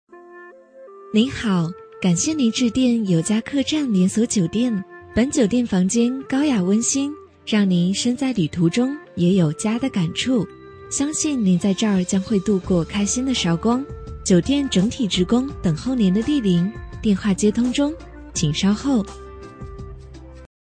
【女57号彩铃】有家客栈甜美带音乐.mp3